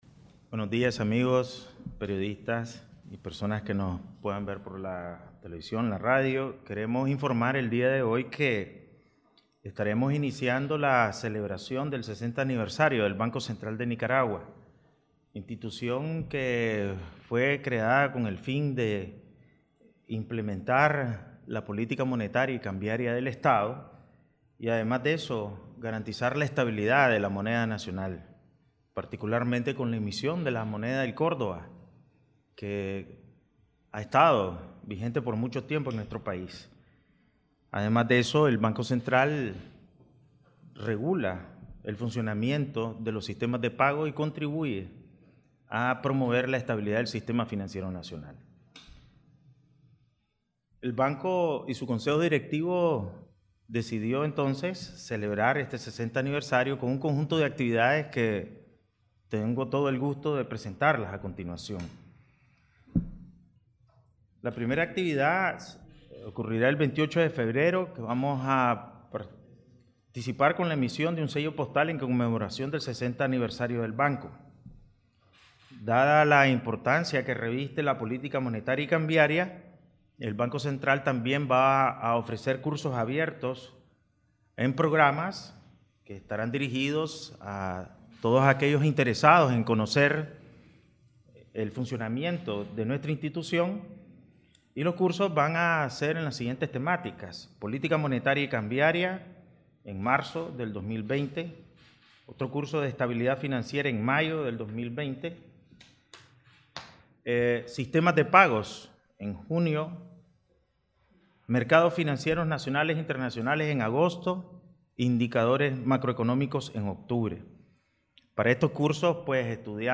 Palabras del presidente del BCN